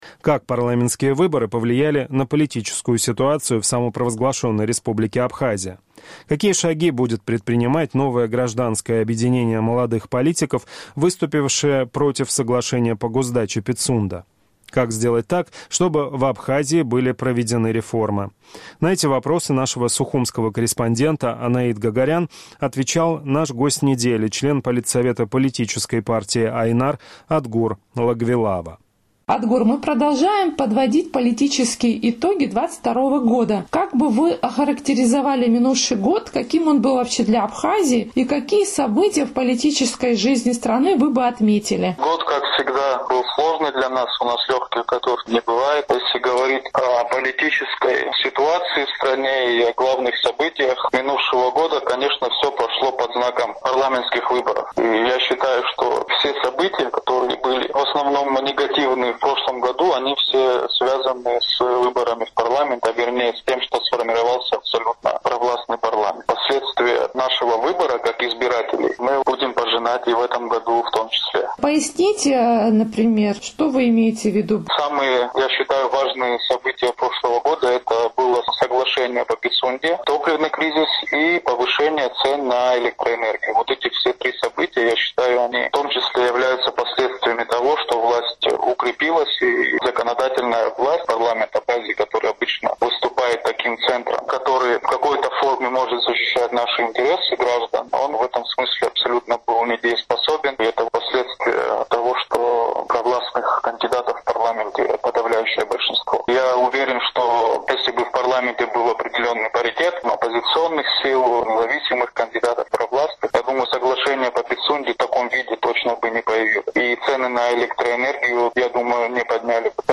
Как парламентские выборы повлияли на политическую ситуацию в Абхазии? Какие шаги будет предпринимать новое гражданское объединение молодых политиков? Какие есть инструменты, чтобы в Абхазии были проведены реформы, в интервью «Эху Кавказа» рассказал член политсовета политической партии «Айнар»...